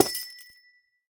Minecraft Version Minecraft Version latest Latest Release | Latest Snapshot latest / assets / minecraft / sounds / block / amethyst / break4.ogg Compare With Compare With Latest Release | Latest Snapshot
break4.ogg